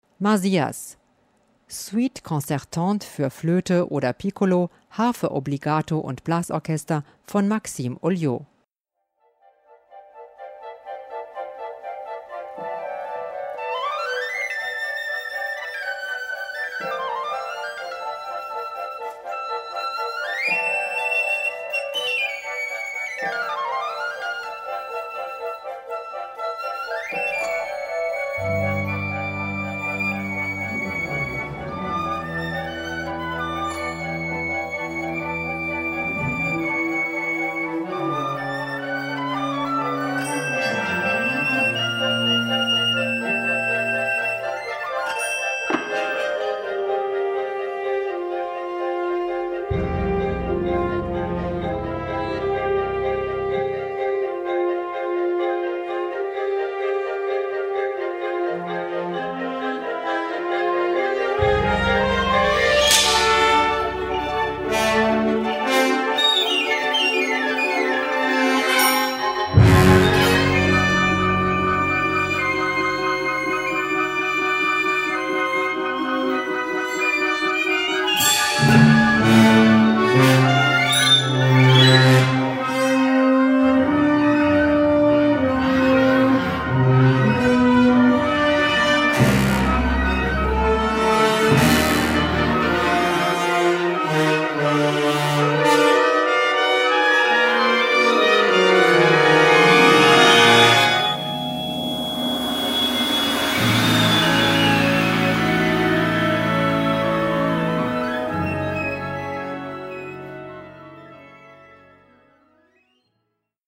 Gattung: Solo für Flöte und Blasorchester
Besetzung: Blasorchester